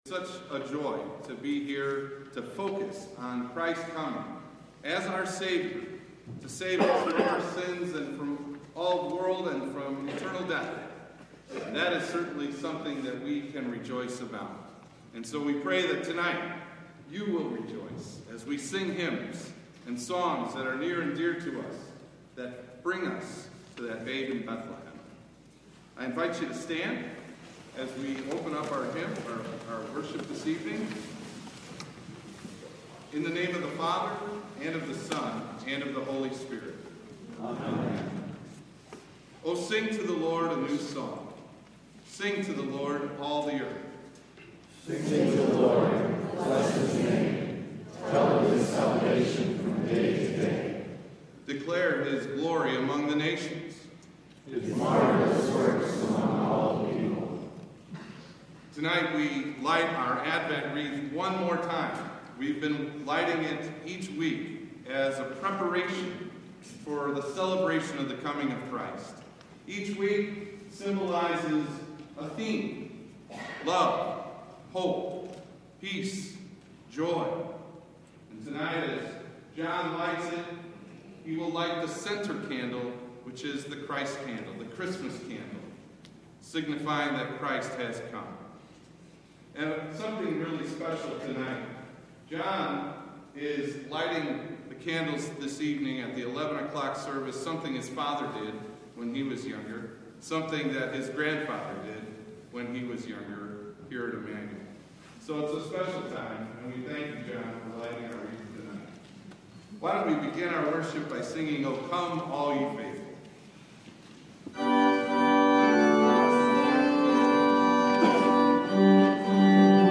Dec 24 / 11:00pm Christmas Eve – An Angelic Message For All Time – Lutheran Worship audio